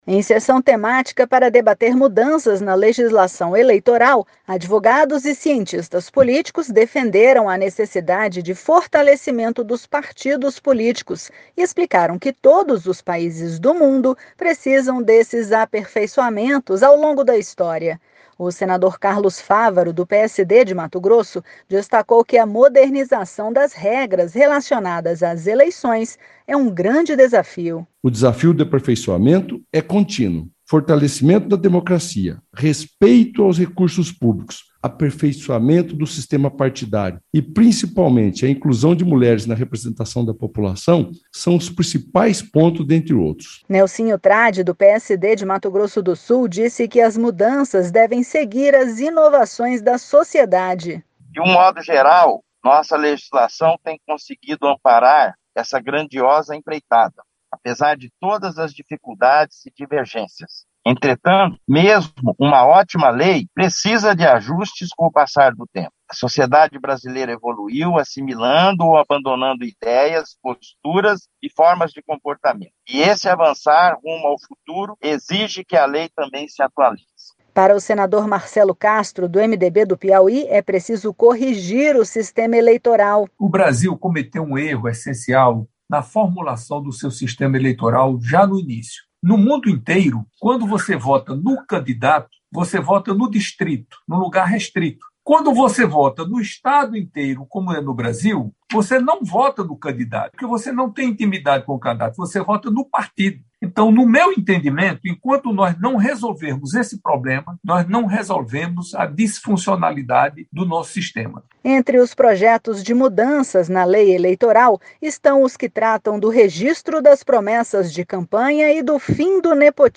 Senadores e especialistas debateram mudanças na legislação eleitoral em uma sessão temática, nesta segunda-feira (5).